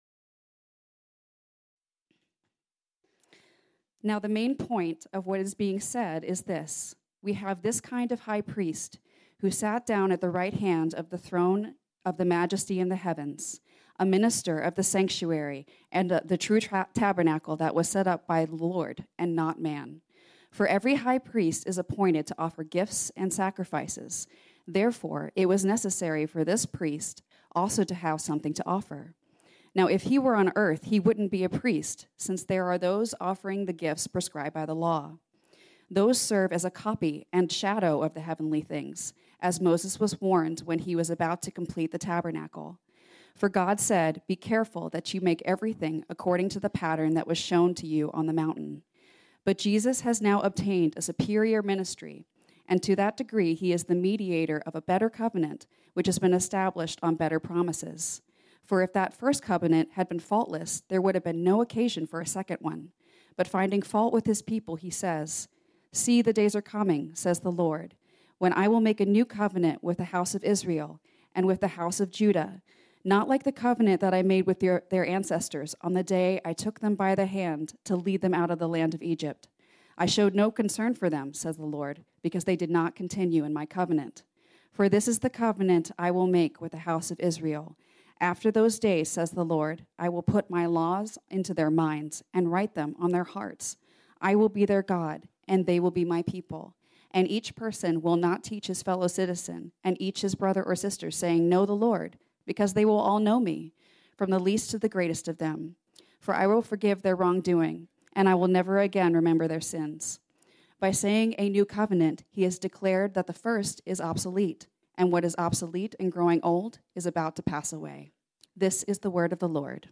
This sermon was originally preached on Sunday, January 8, 2023.